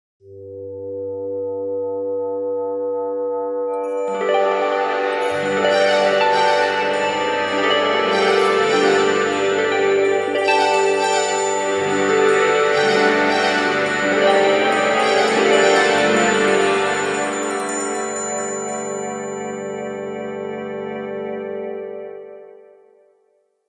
» Мелодичный будильник Размер: 142 кб